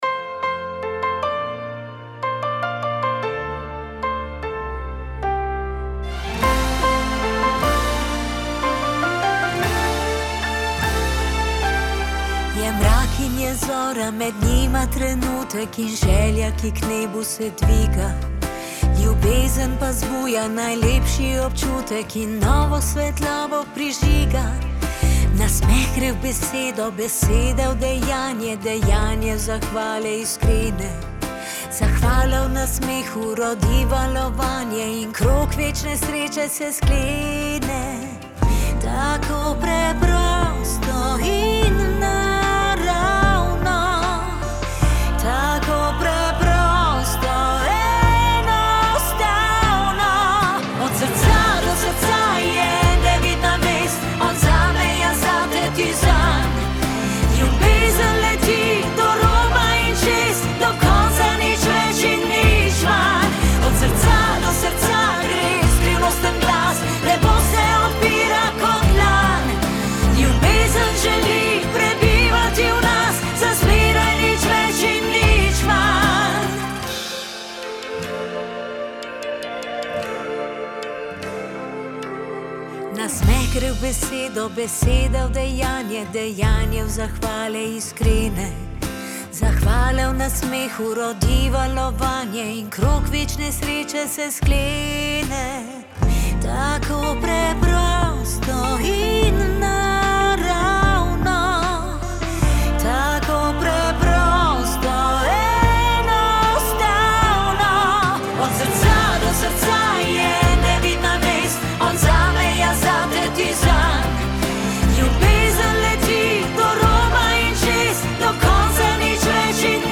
priredbo za simfonični orkester in radijske postaje